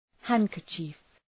Προφορά
{‘hæŋkərtʃıf}